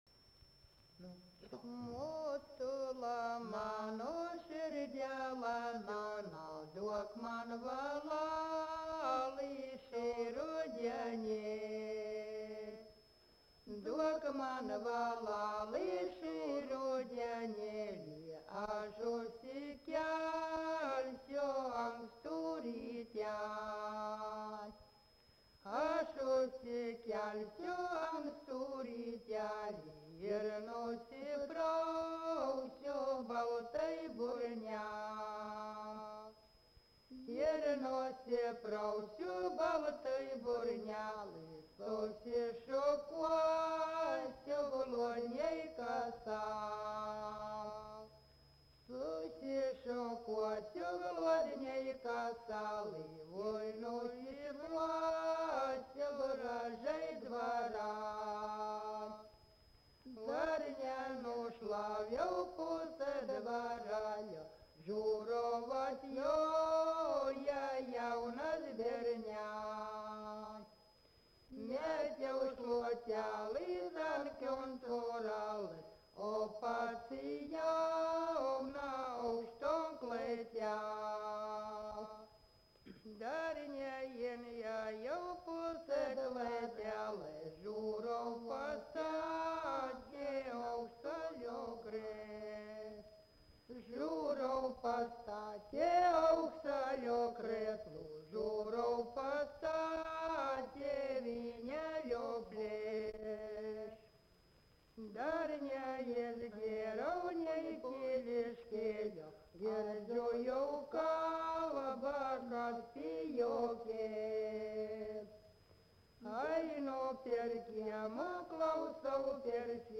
Dalykas, tema daina
Erdvinė aprėptis Viečiūnai
Atlikimo pubūdis vokalinis